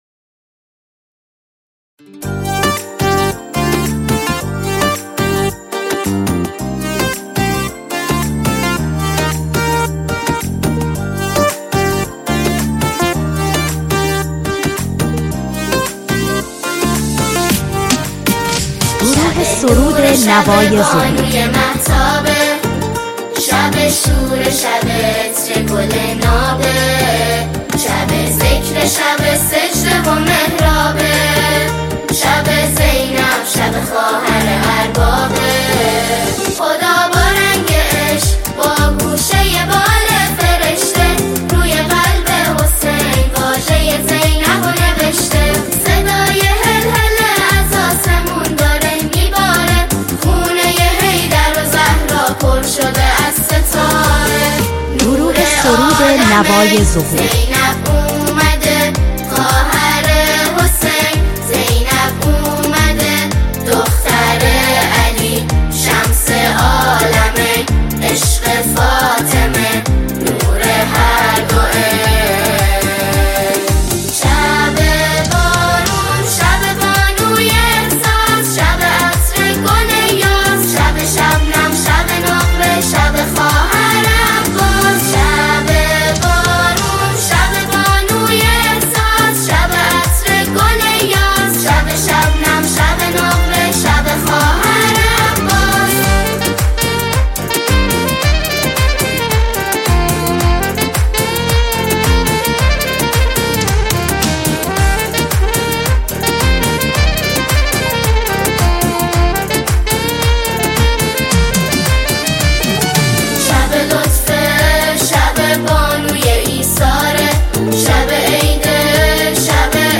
پر احساس و معنوی
ژانر: سرود